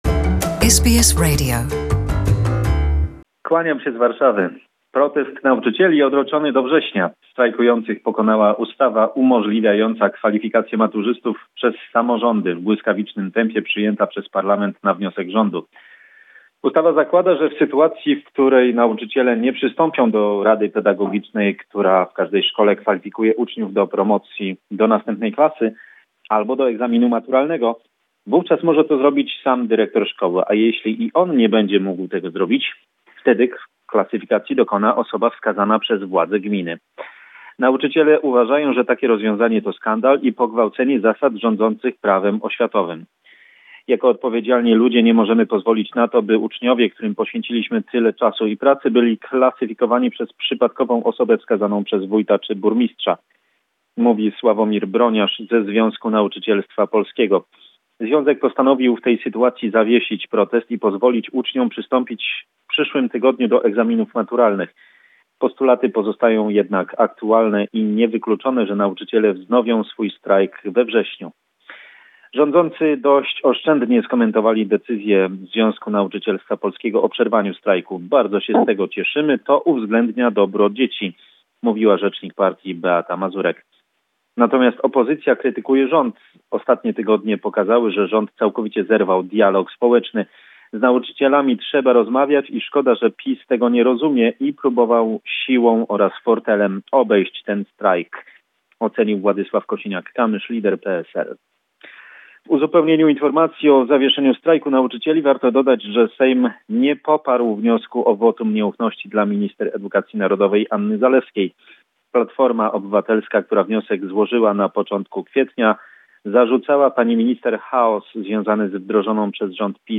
Commentary